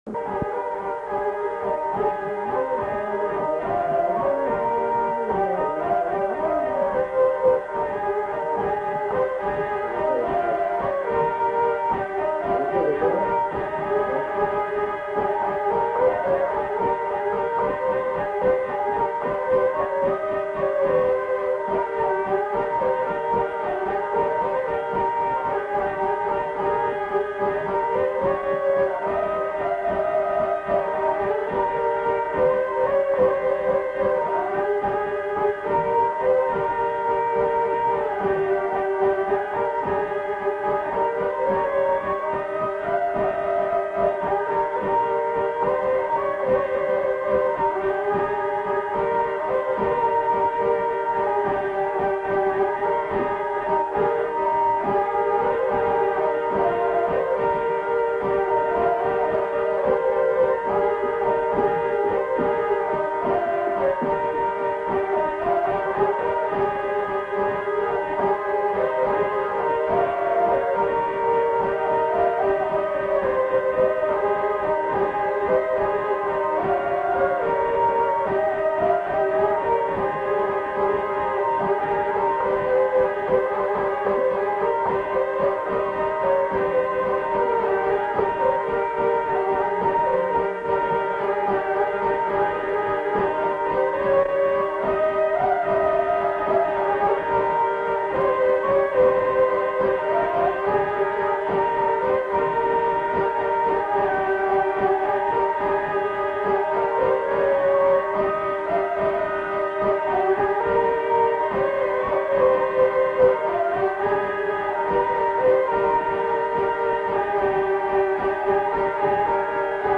Aire culturelle : Bas-Armagnac
Lieu : Mauléon-d'Armagnac
Genre : morceau instrumental
Instrument de musique : vielle à roue
Danse : valse